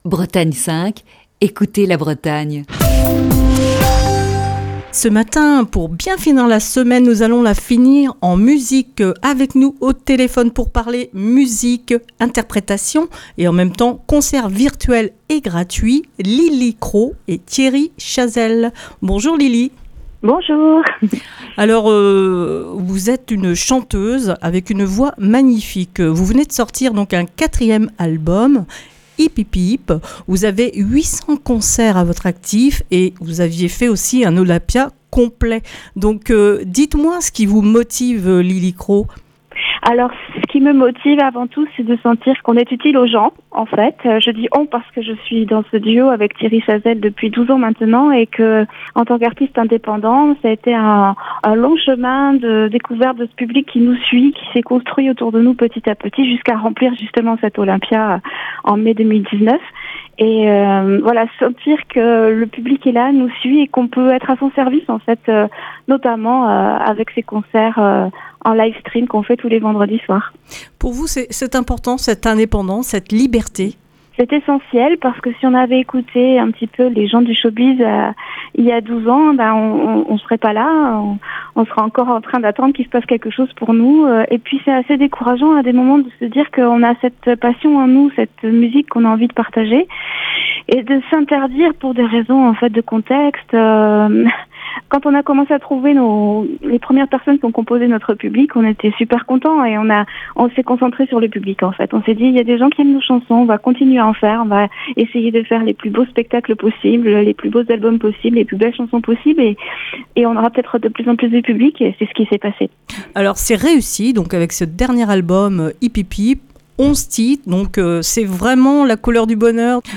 un Coup de fil du matin musical